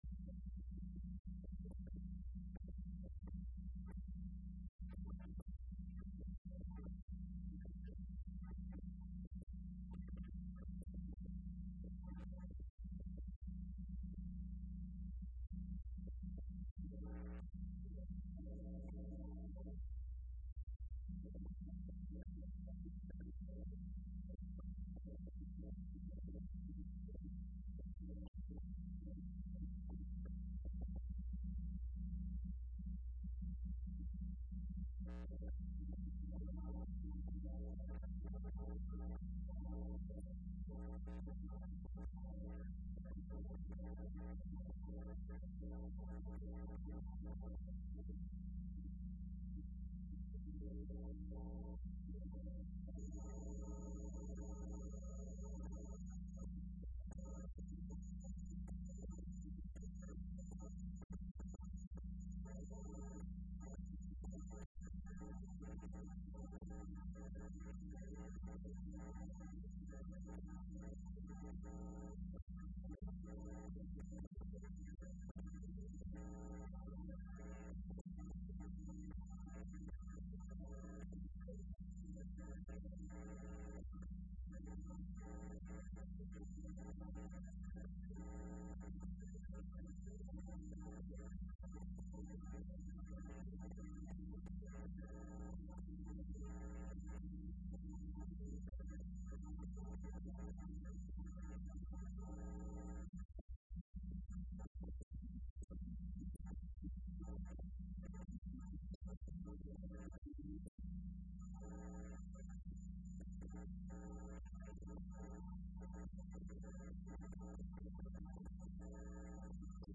Entrevista Opinón Universitaria(9 de Noviembre 2015): Trabajo a realizar por parte de las nuevas autoridades Facultad de Jurisprudencia y Ciencias Sociales,periodo 2015-2019.